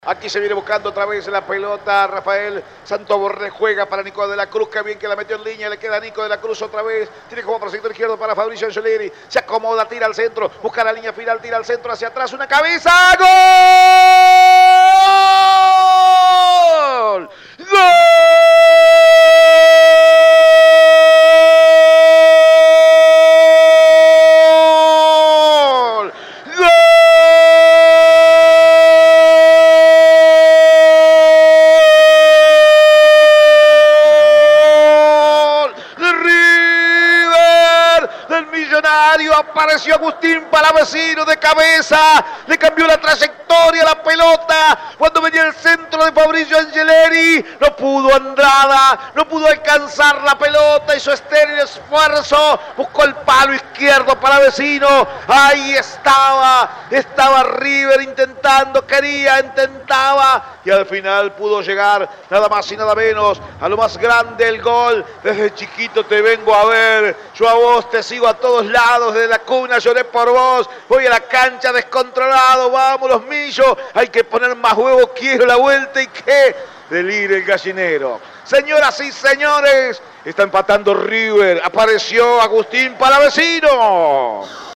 01-GOL-RIVER-EDITADO.mp3